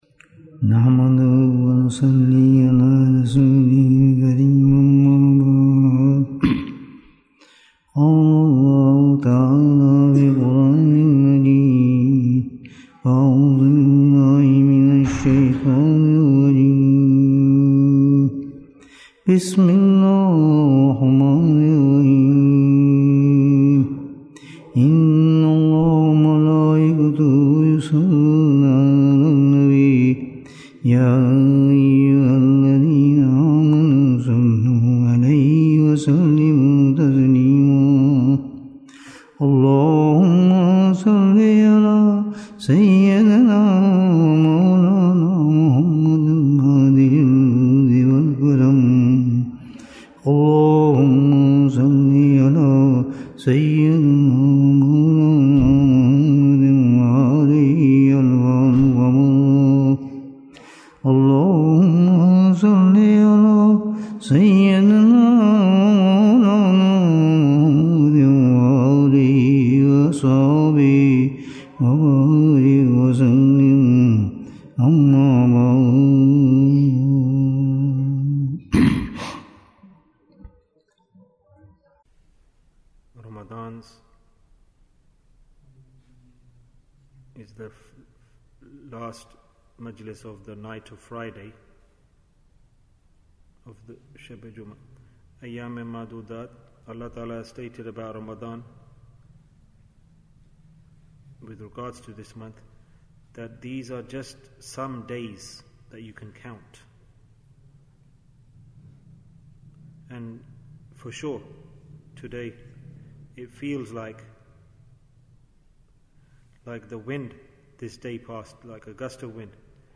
Farewell Ramadhan Bayan, 80 minutes20th April, 2023